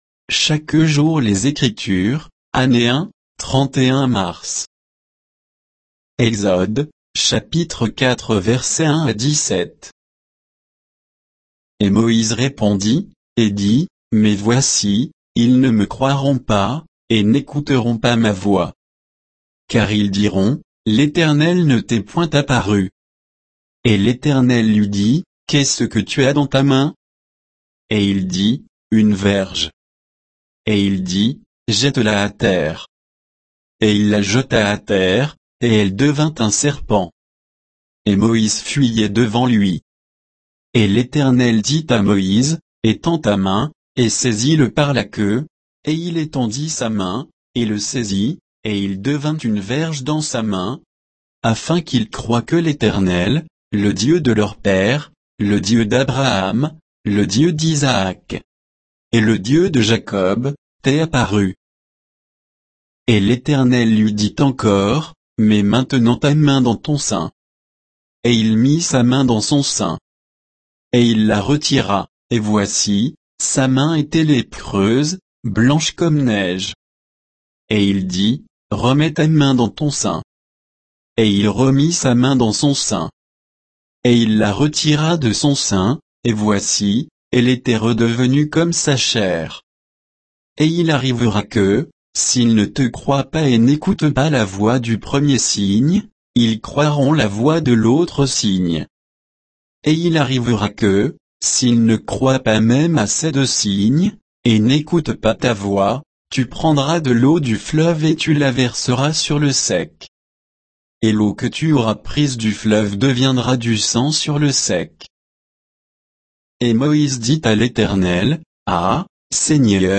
Méditation quoditienne de Chaque jour les Écritures sur Exode 4